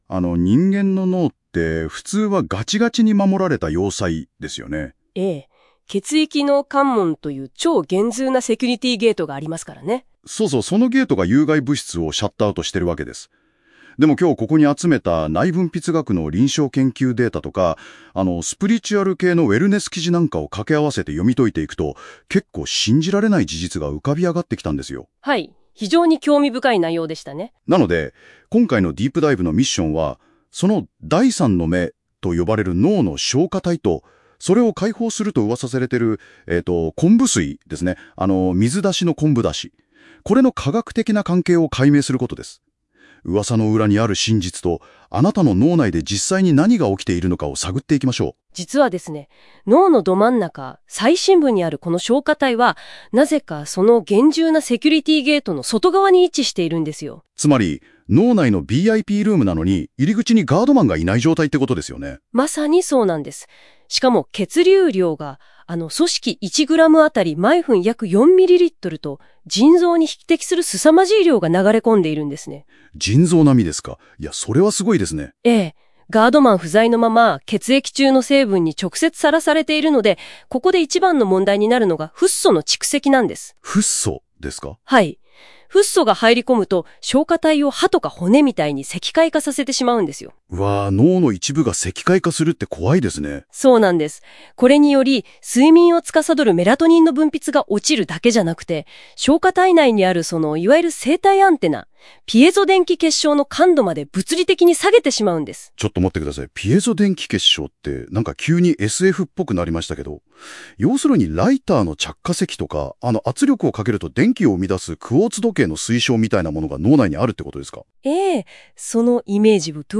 【音声解説】昆布水で松果体のフッ素を排出する